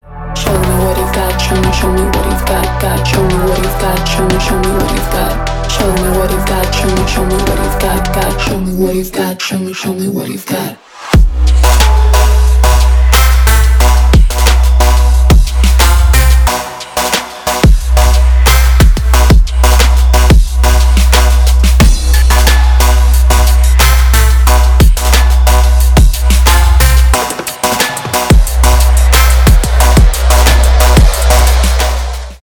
Electronic
Trap
чувственные
красивый женский голос
Indietronica
Оригинальная смесь трэпа и индитроники